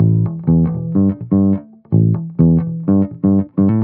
19 Bass Loop A.wav